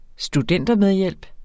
Udtale [ sduˈdεnˀdʌ- ]